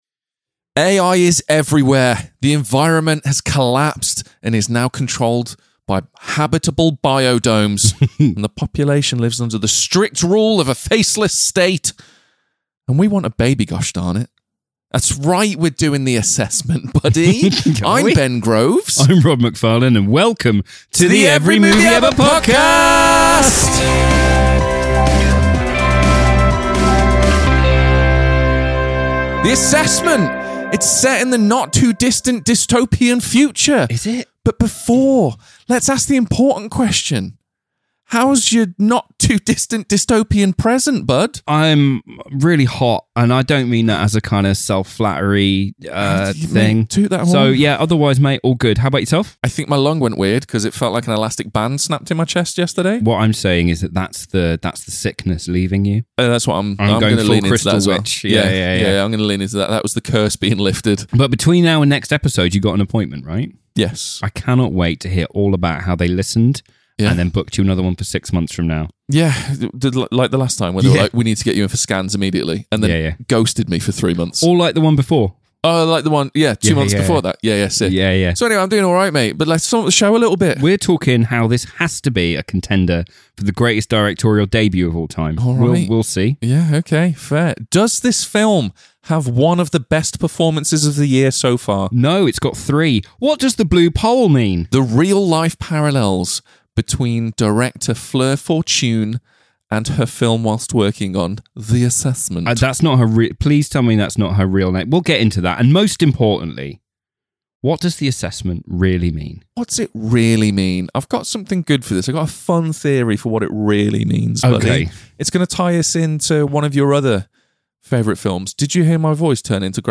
Weekly episodes released every Sunday have the boys talking everything from Hollywood blockbusters to indie darlings and all manner of highs and lows in-between!